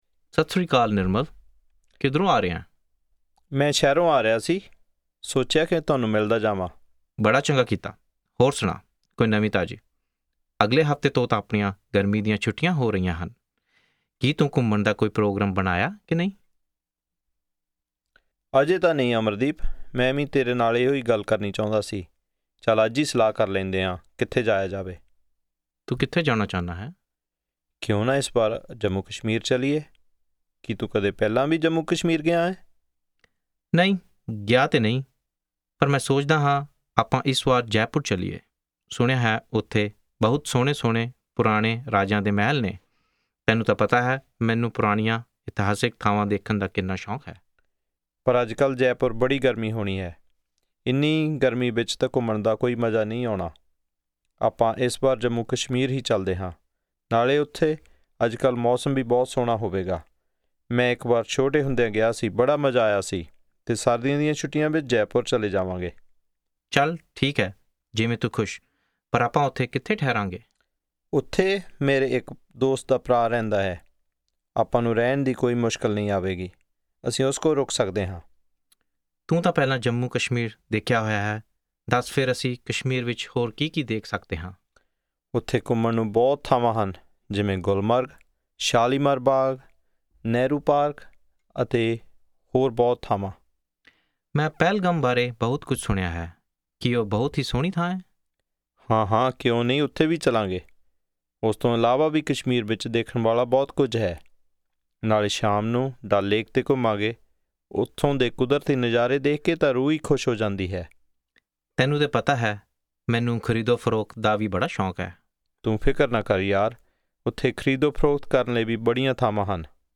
Punjabi Conversation 13 Listen